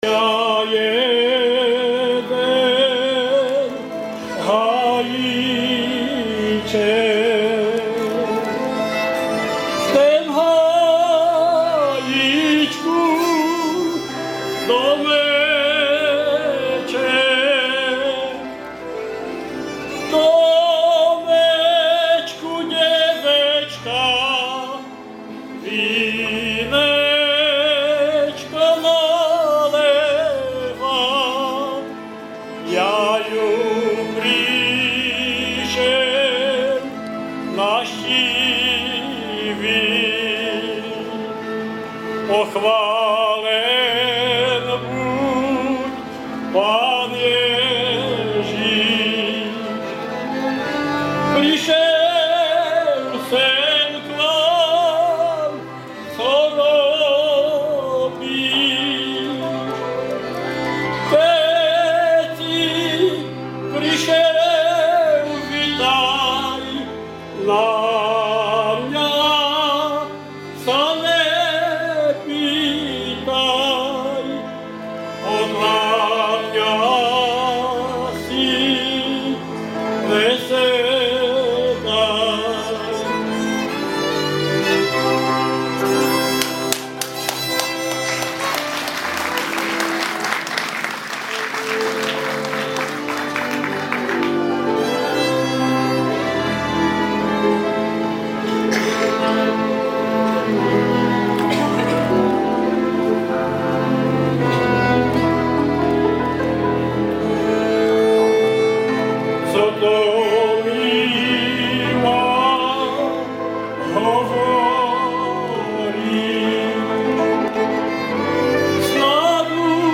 1. hlas zvuková nahrávka
Kategorie: Rhytm&Blues